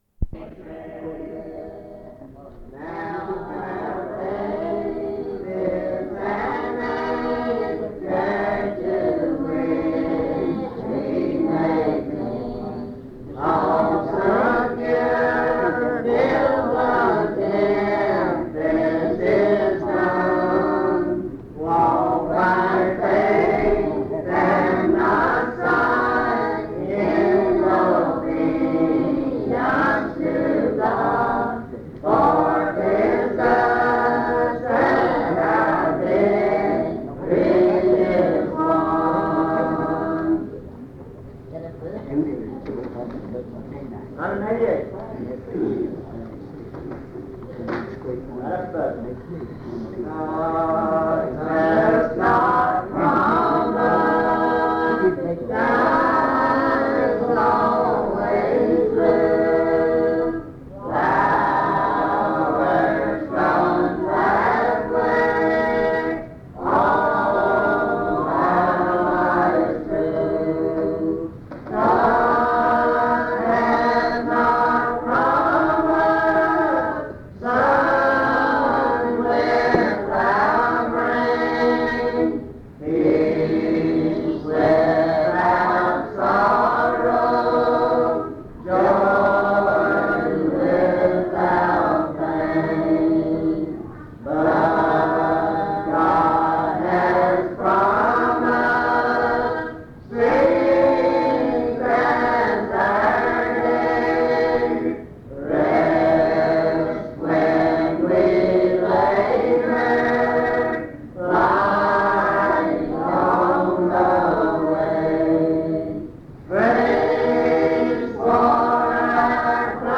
Recording of a service including sermons by three elders; The order of the elders is somewhat unclear.